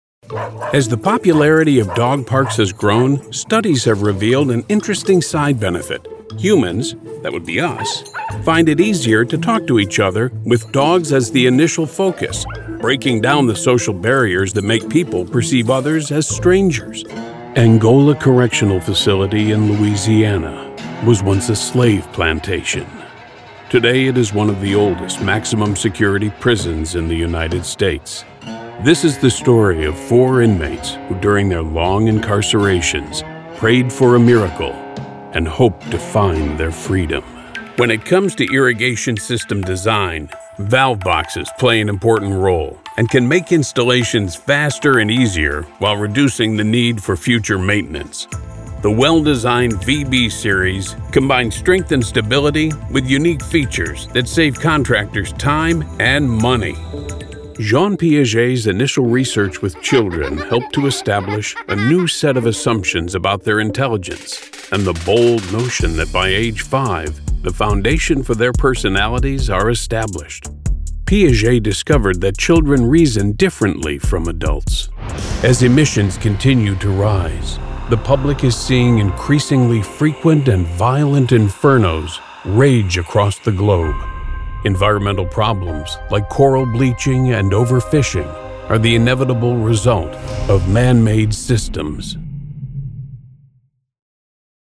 Your story in a voice that is confident, commanding and as comfortable as that first cup of coffee on a Saturday morning.
Video Narration
English (North American)
Middle Aged
I record in an enclosed sound booth using either a Sennheiser MKH 416 or a Neumann TLM 102 into a SSL2 Audio Interface and edited on Adobe Audition.
Non-Broadcast Demo 2021.mp3